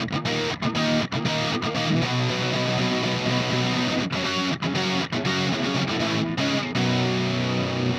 Power Pop Punk Guitar 02c.wav